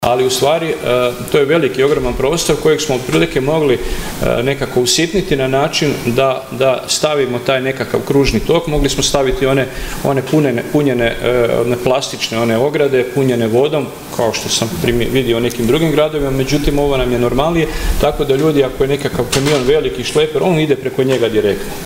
SNIMAK SA SJEDNICE GRADSKOG VIJEĆA, VINKO GRGIĆ: